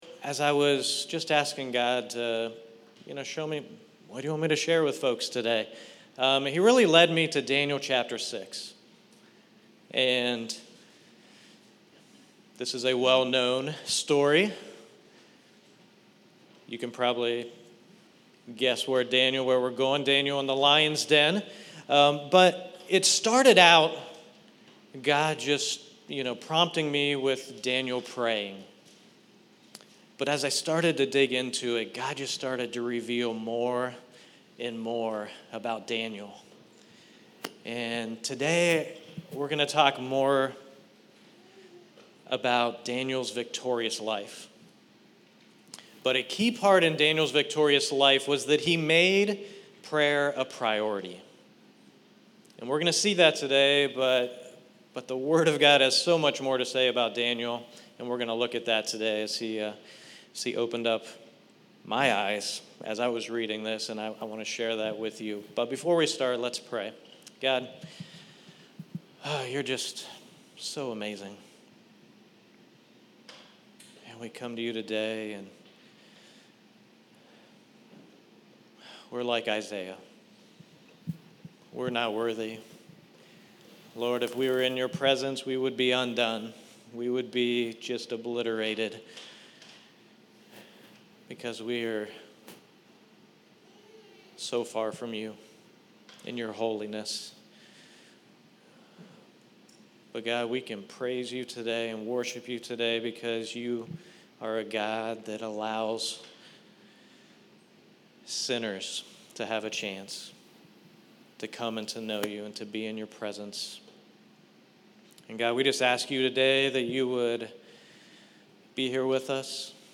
Cincy Gathering